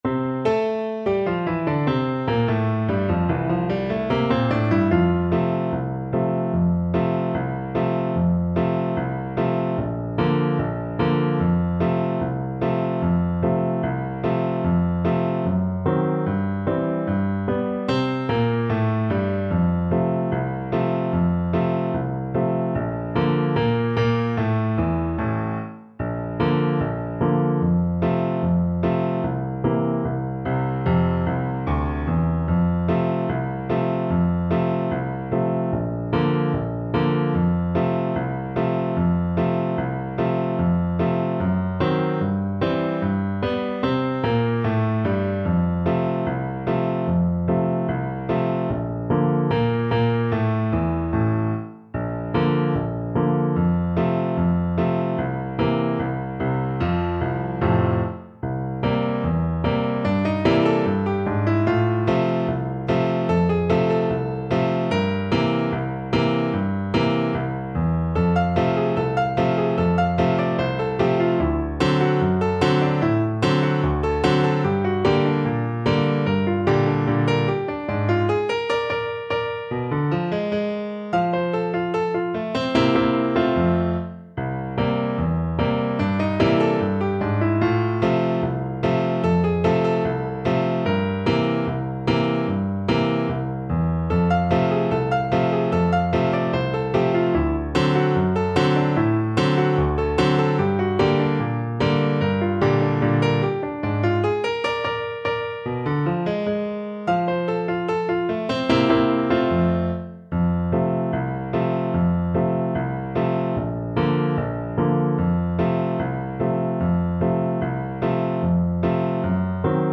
Not Fast = 74